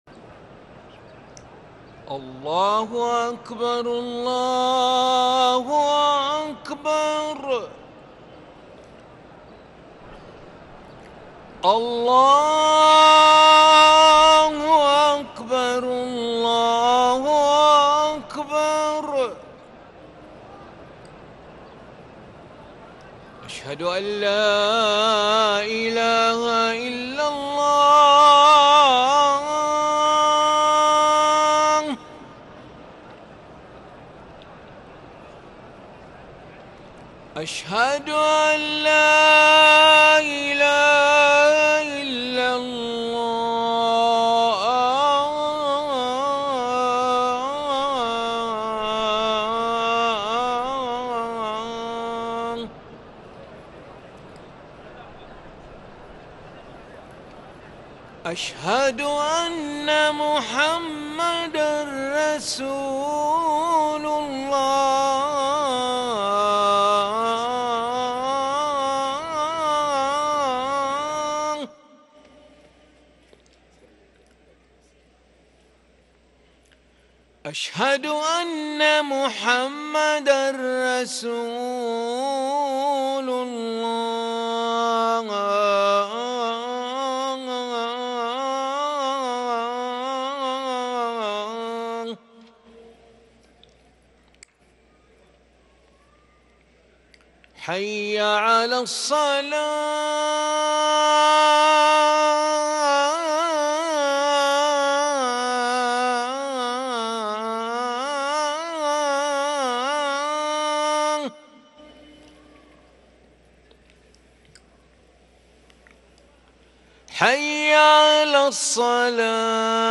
أذان العشاء للمؤذن علي ملا الأحد 13 ربيع الأول 1444هـ > ١٤٤٤ 🕋 > ركن الأذان 🕋 > المزيد - تلاوات الحرمين